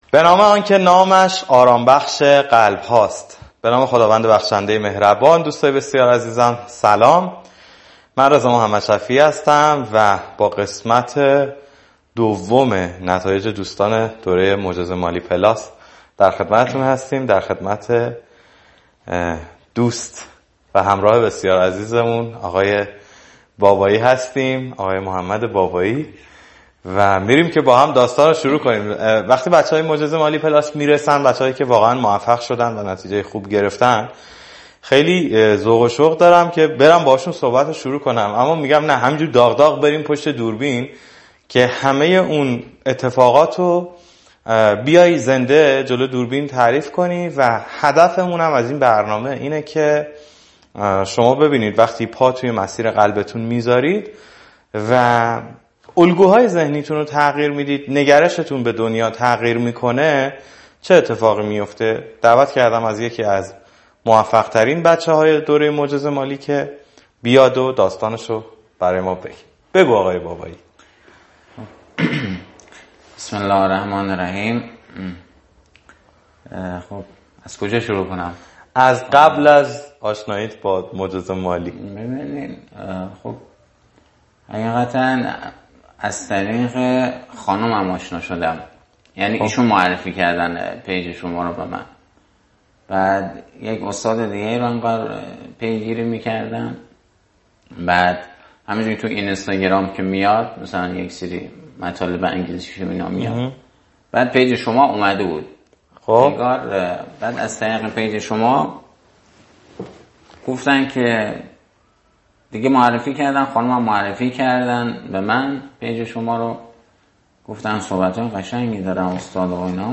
خیلی مصاحبه ی اثرگذاری شد در حدی که هر دوی ما وسطش گریه کردیم.